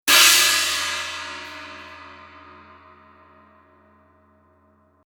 破裂音のような抜けの良いアタックと独特のサスティーンでエフェクト、アクセントに最適。スティックワークによってさまざま表情を持ち独特の形状をしたカップはオーバートーンを防ぎます。